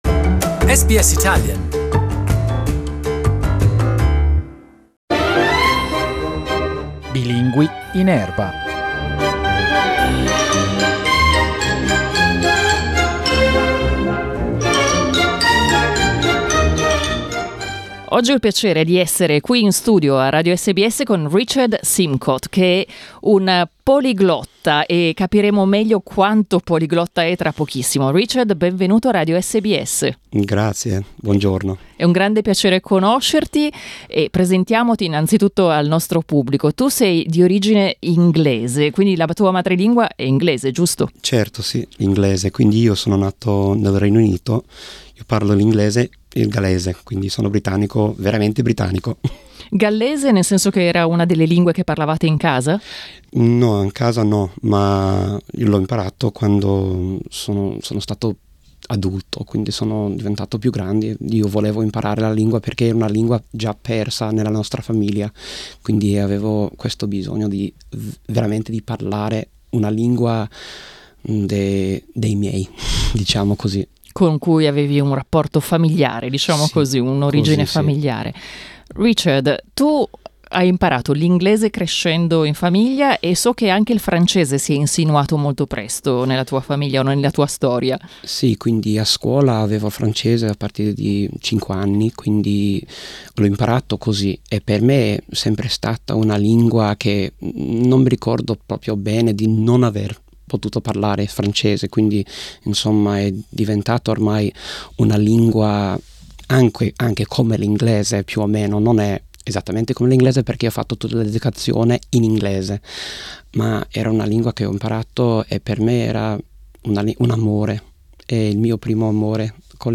Cliccate sulla foto in alto per ascoltare il podcast della nostra intervista.